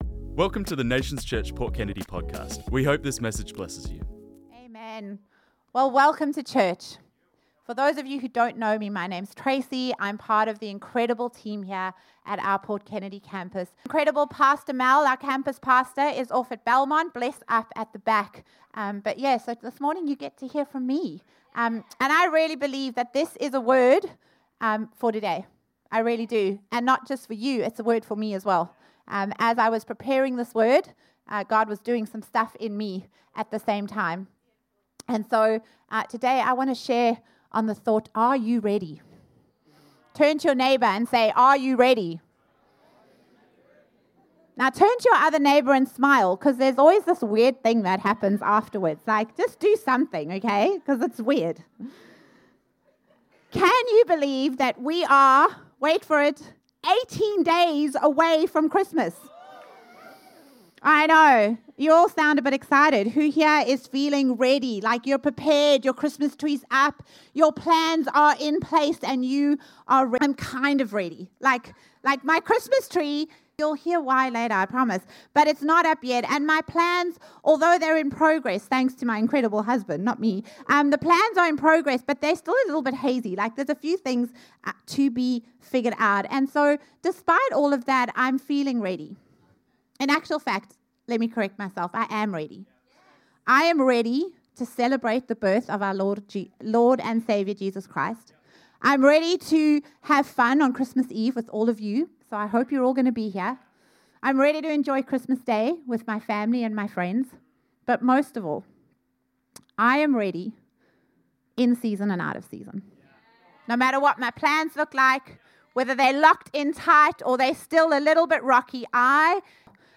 This message was preached on Sunday the 7th December 2025